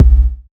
Tuned drums (B key) Free sound effects and audio clips
• Round Kick Sample B Key 431.wav
Royality free bass drum sound tuned to the B note. Loudest frequency: 105Hz
round-kick-sample-b-key-431-dfb.wav